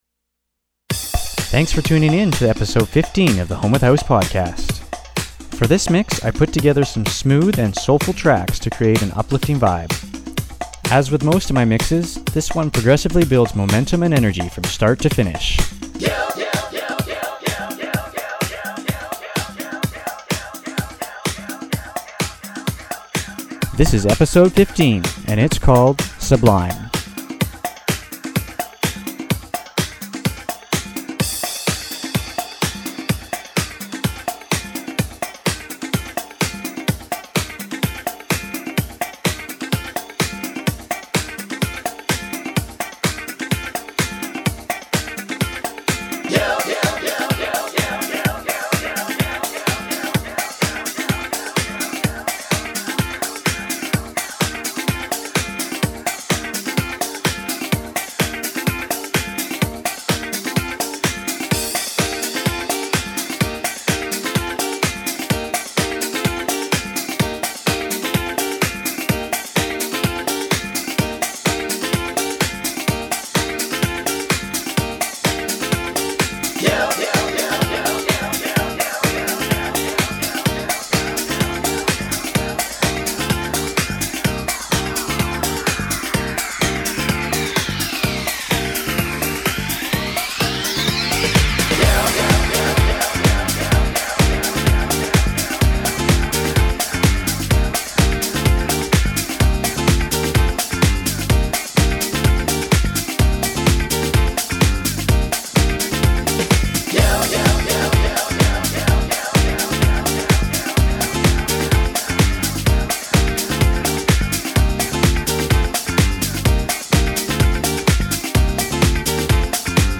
Vibe: Soulful House , Uplifting House , Vocal House